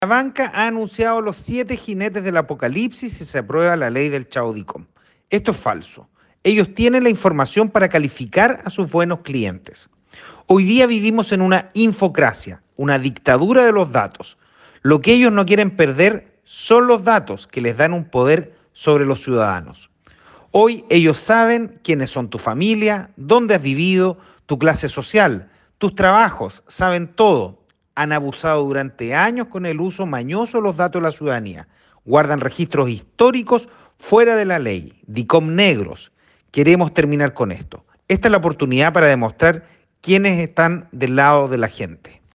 AUDIO Diputado Daniel Manouchehri